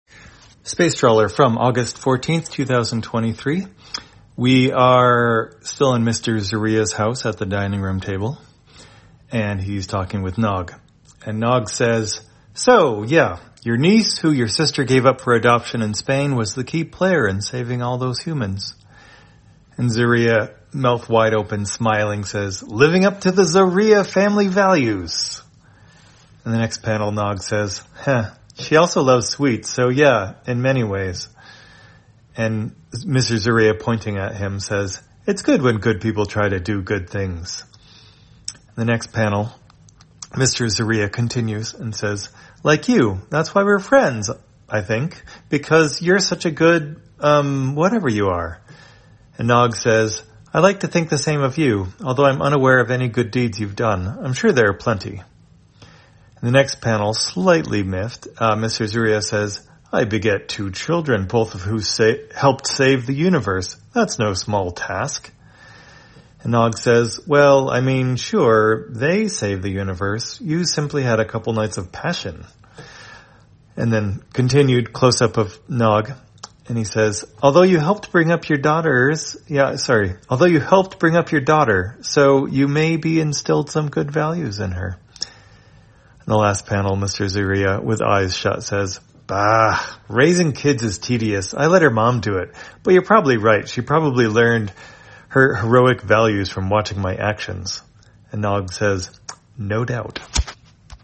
Spacetrawler, audio version For the blind or visually impaired, August 14, 2023.